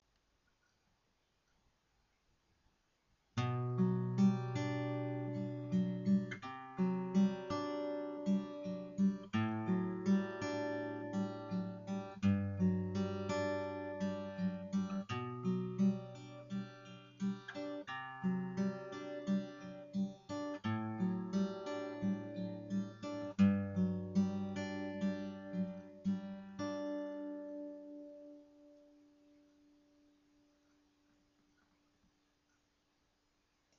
微妙なアルペジオ
すごく簡単なアルペジオです。 うぅ・・・フィンガーは微妙すぎる・・・・。
arpeggio.mp3